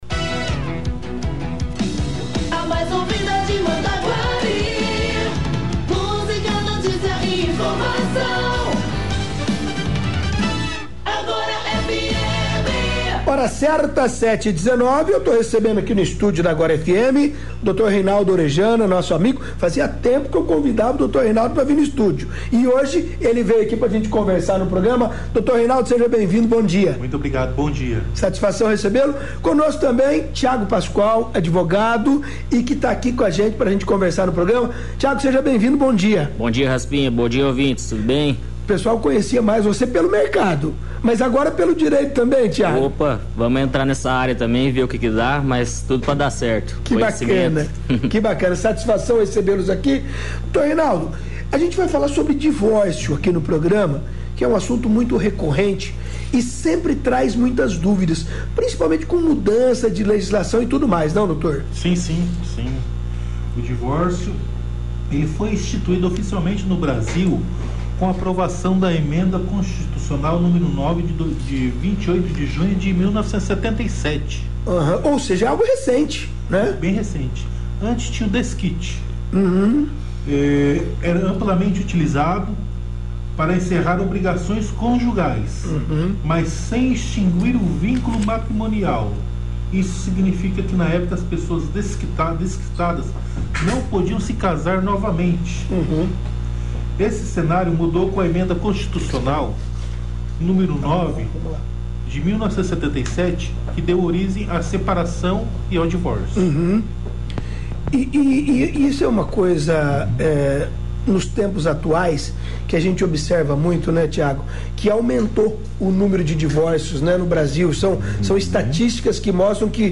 Em entrevista no Show da Manhã na Agora FM (87,7Mhz)